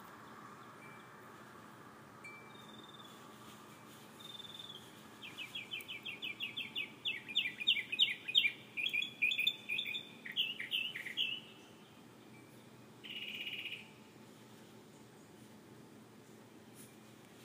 I sat on the wall and listened to the birds sing.
I closed my eyes and listened to the birds call and sing to each other.